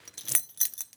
foley_keys_belt_metal_jingle_09.wav